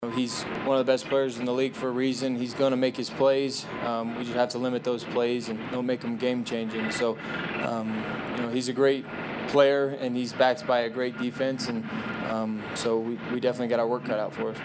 Broncos quarterback Bo Nix on facing Green Bay edge rusher Micah Parsons for the first time.